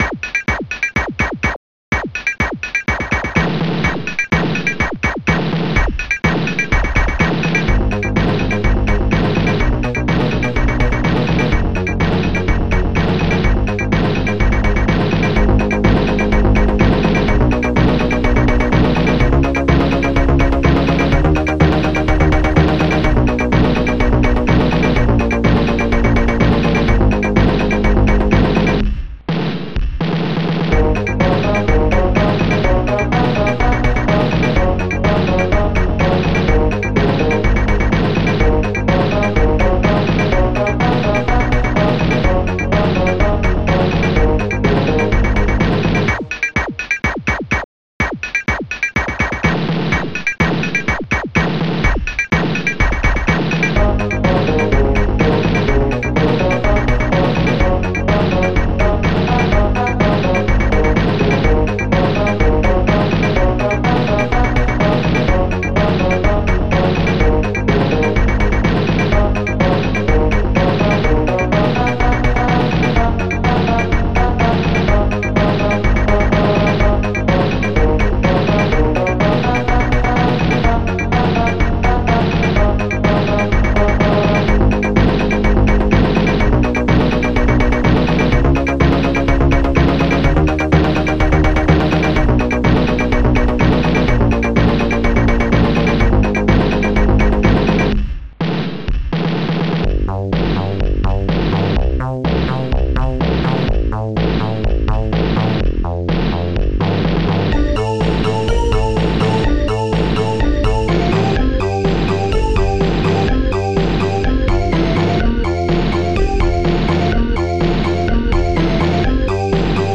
st-08:bass14
ST-03:flute
st-01:strings4